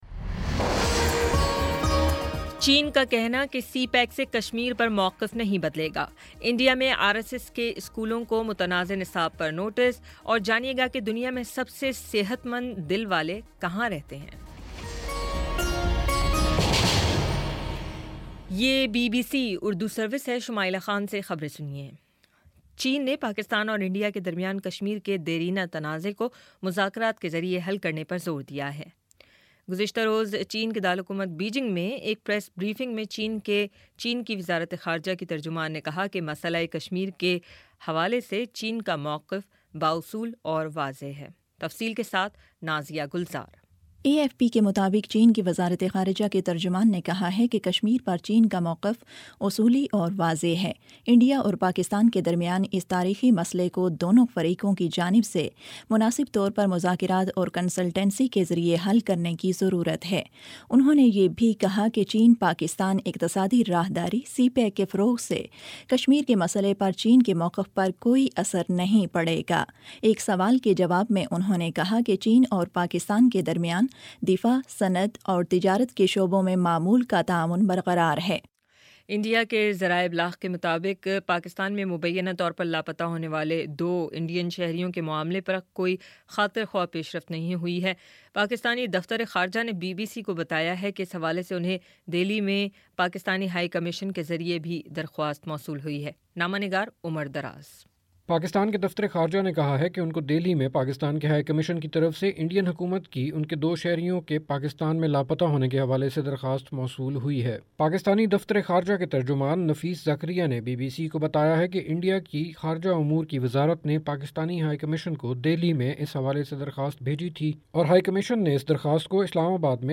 مارچ 18 : شام چھ بجے کا نیوز بُلیٹن